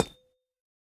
Minecraft Version Minecraft Version latest Latest Release | Latest Snapshot latest / assets / minecraft / sounds / block / copper / step2.ogg Compare With Compare With Latest Release | Latest Snapshot
step2.ogg